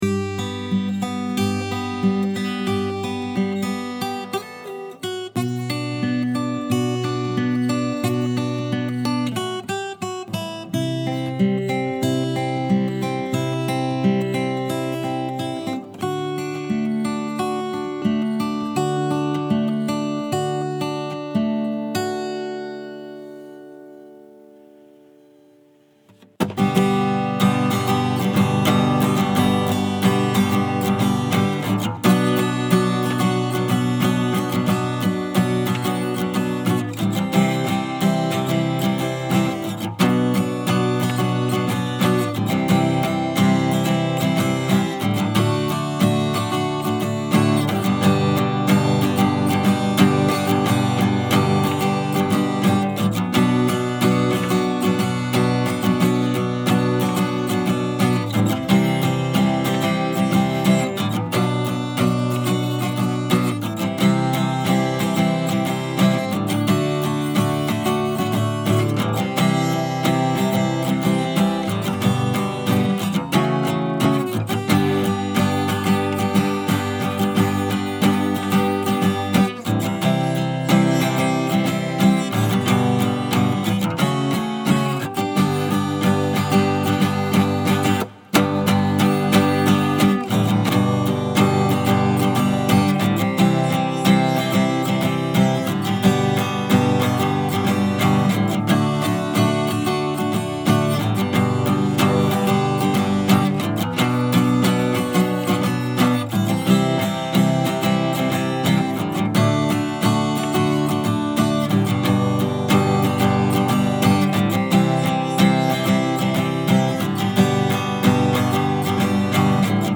おまけ：アコースティックバージョン
私が仮歌を歌いましたが、アコースティック映えする曲だったのでギターの弾き語りにもチャレンジしました🙌
ギターを持ってない方のために、私の弾き語り音源も配布します✉
音がはっきり出てなかったり、カツカツピックが当たる音が入ってたり、なんなら鼻をすする音まで入ってるけど、これでもいい人は使ってみてね😽
yohaku-acoustic.mp3